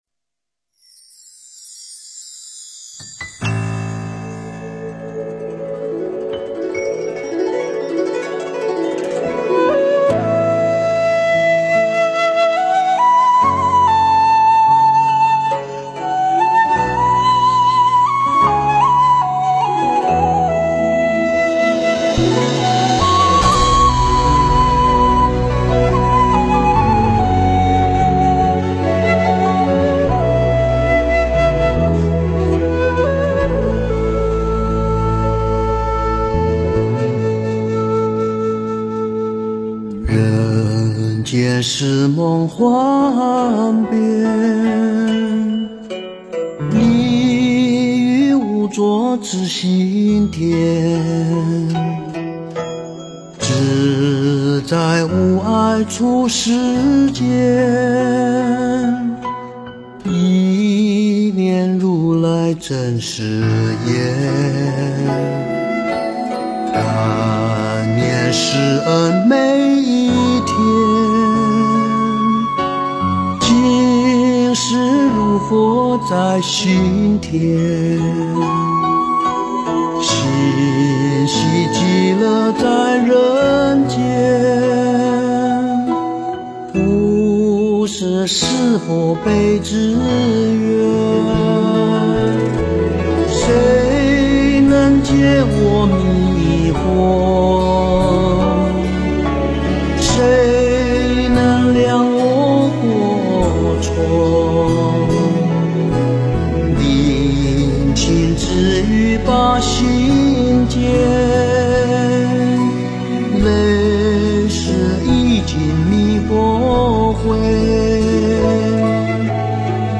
佛音 诵经 佛教音乐 返回列表 上一篇： 祈愿(点灯 文